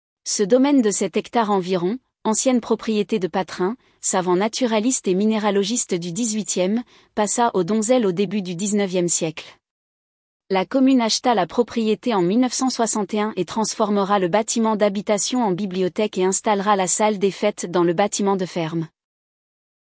audio guide du Clos Donzel